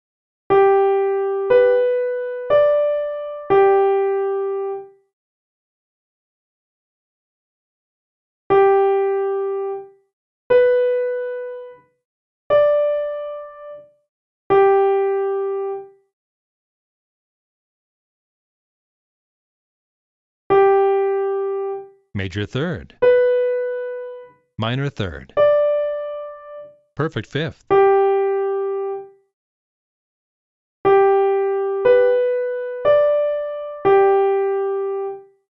2. If you need a hint, listen to the hint clip which will play the melody more slowly and then reveal the intervals between each pair of notes.
Finally, let’s return to short and slow melodies but introduce a larger range of intervals.
Key: G Major, Starting Note: G
Hard_Melody_Intervals_1.mp3